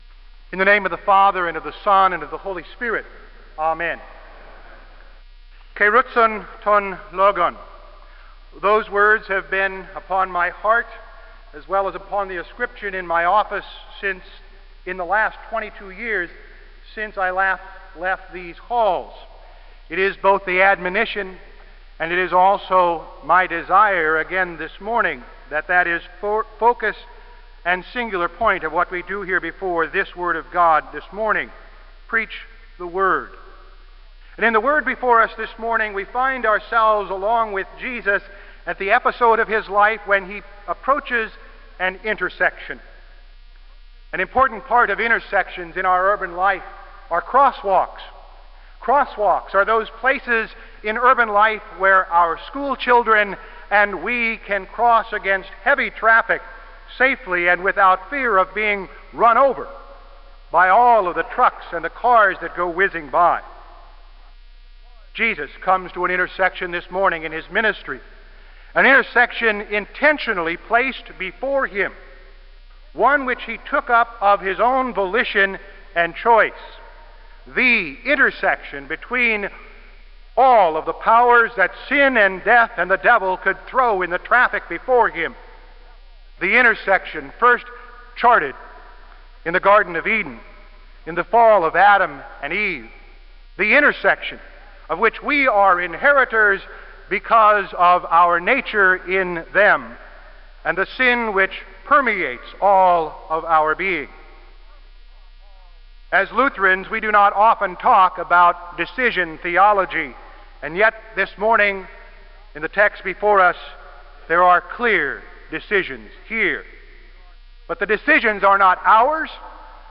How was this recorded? Kramer Chapel Sermon - March 15, 2000